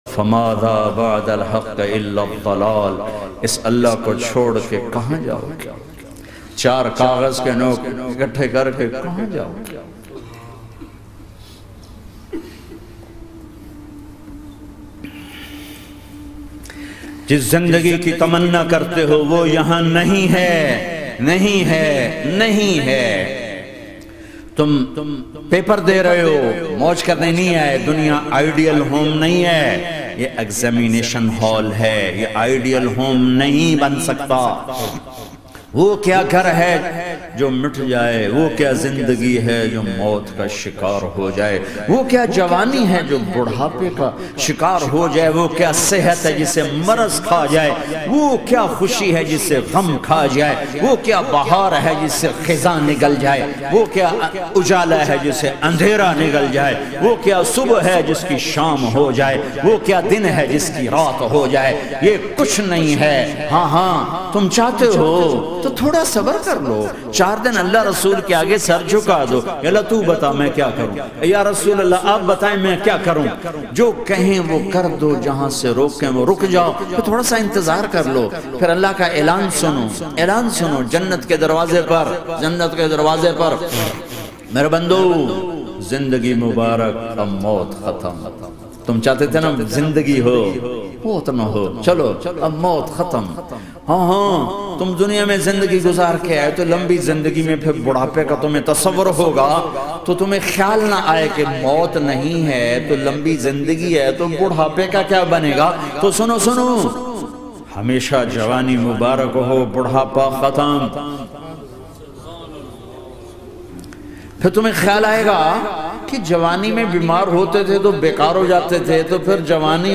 Jannat Ke Khubsoorat Manzar Aur Elanat (Announcements) - Amazing Bayan By Maulana Tariq Jameel.mp3